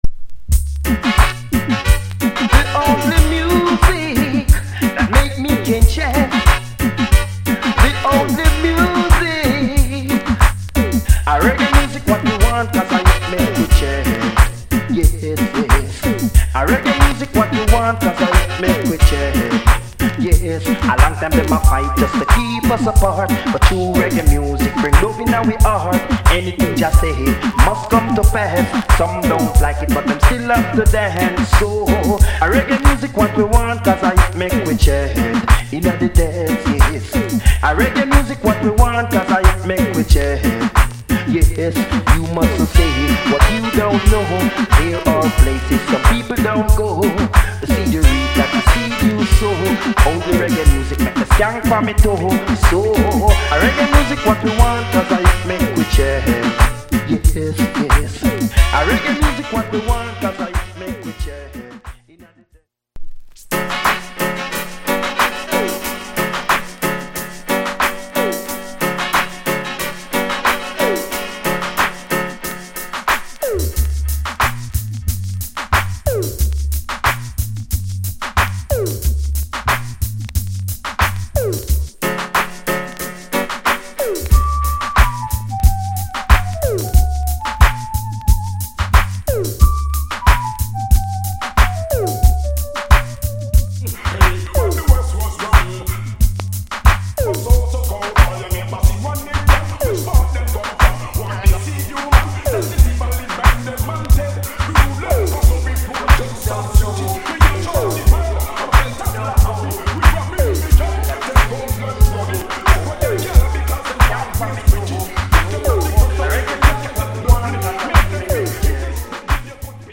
Good Vocal